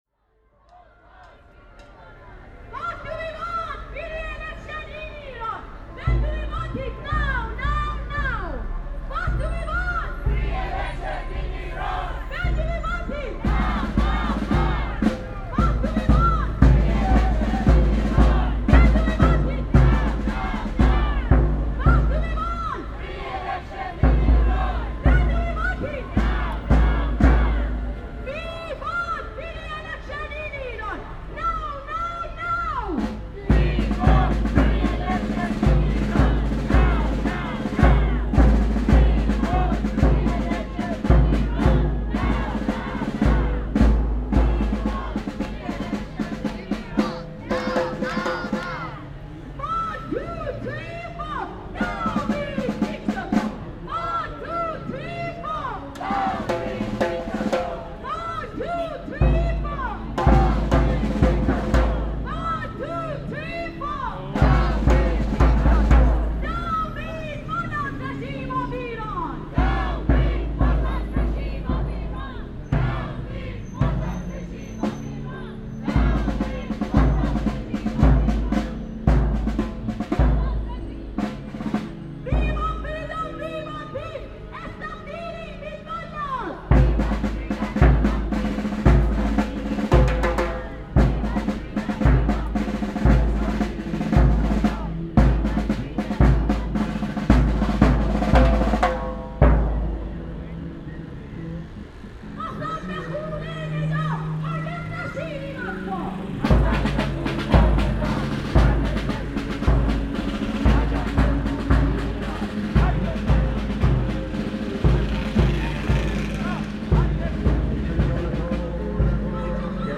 Iranian embassy protest
Tags: Rallies and demonstrations Rallies and demonstrations clips UK London Rallies and demonstrations sounds